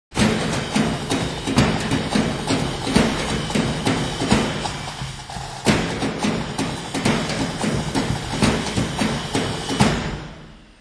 Audio samples are low resolution for browsing speed.
angle grinder
stop sign, cybergong